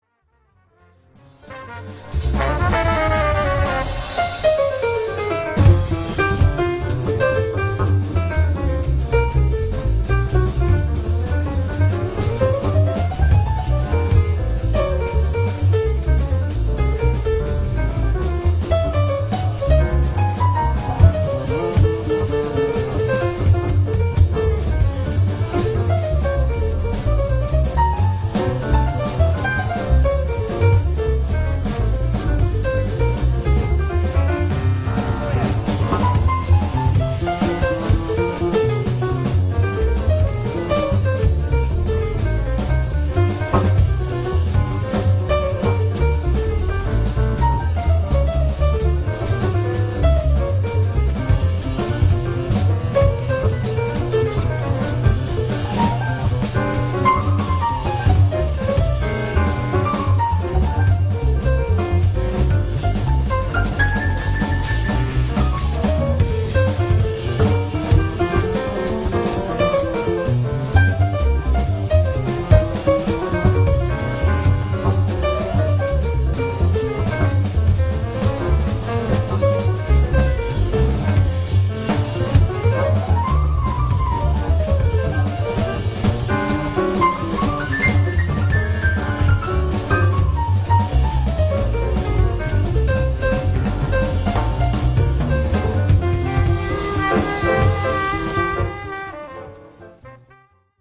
live, raw, and unperturbed by stressful situations.